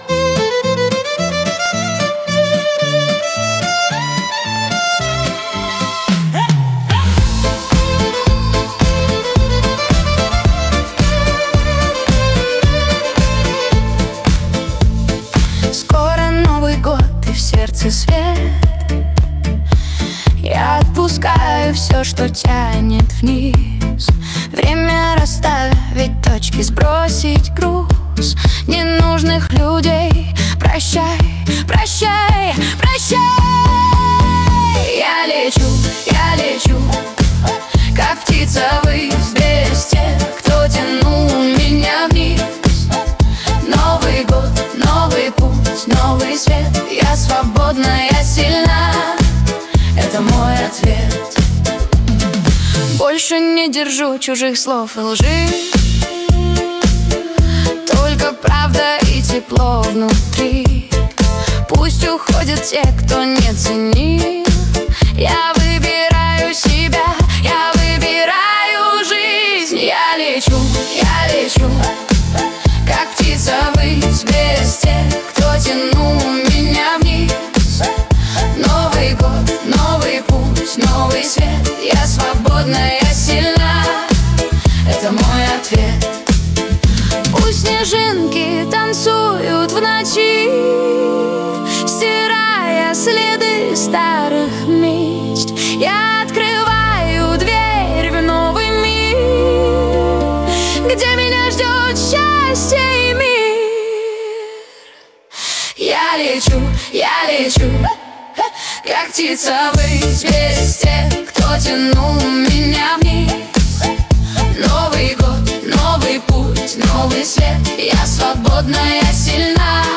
14 декабрь 2025 Русская AI музыка 97 прослушиваний